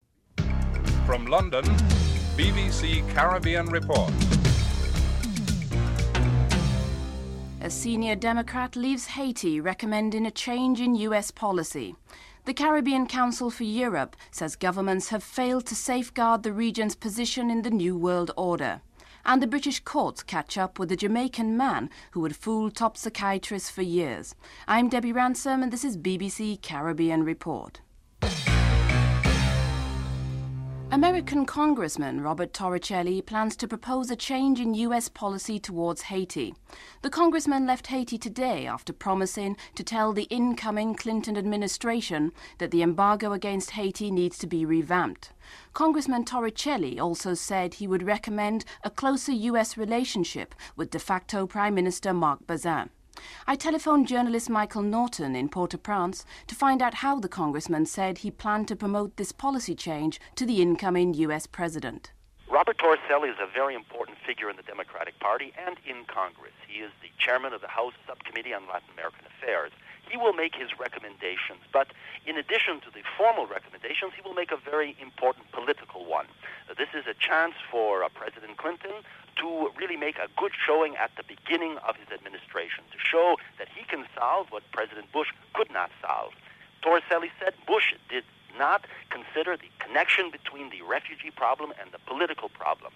1. Headlines (00:00-00:30)